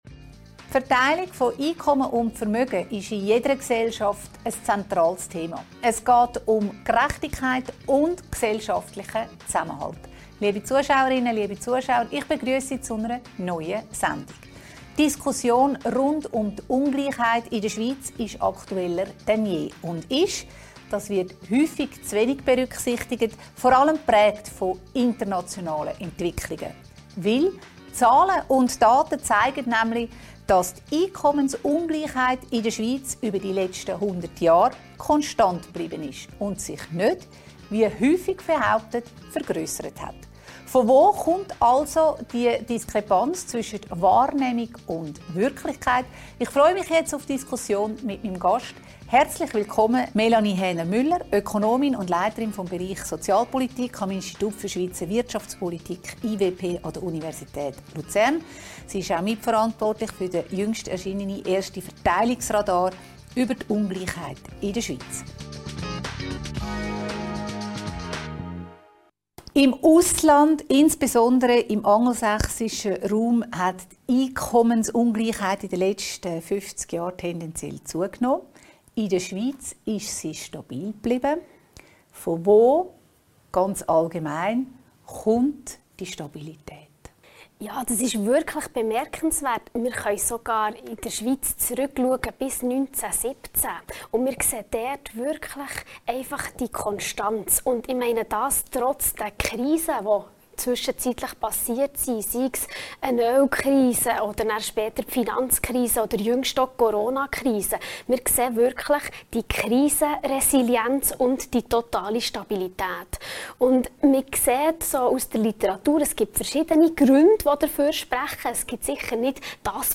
im Gespräch mit Ökonomin